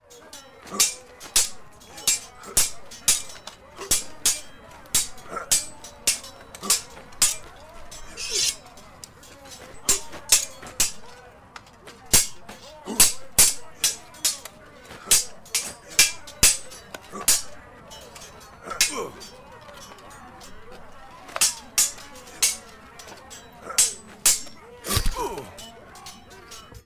Sound design demos
Medieval battle